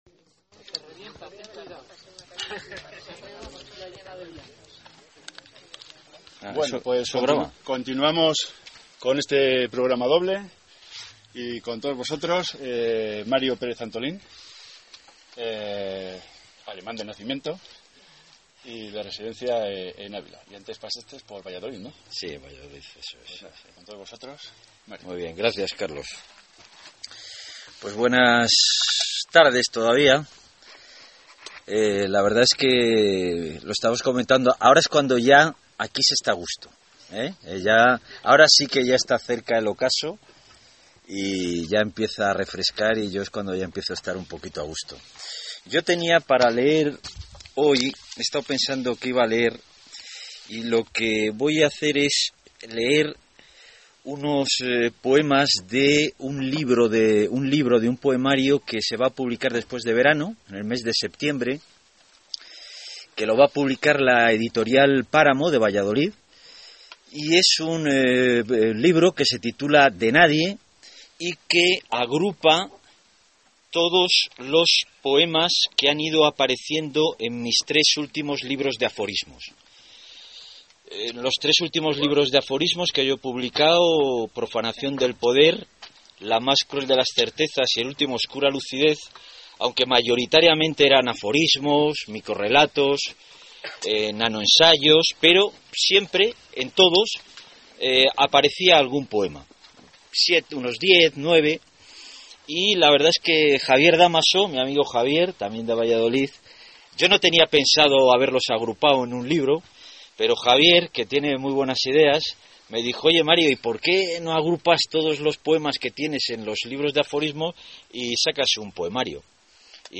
México Audio del recital poético en Cerro Gallinero. 2016 Este año participo en el 12º Festival Internacional de Poesía Abbapalabra de México que se celebrará del 26 de septiembre al 7 de octubre.
AudioZrecitalZCerroZGallinero.mp3